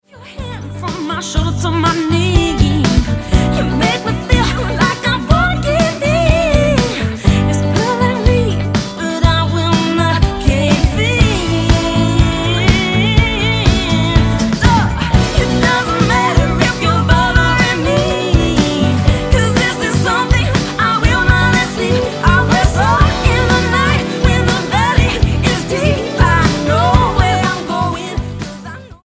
Singing sweet soul music
soul sisters